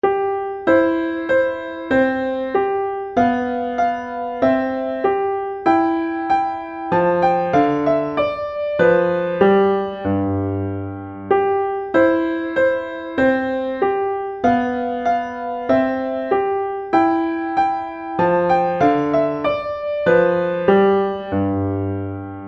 Partitura para piano.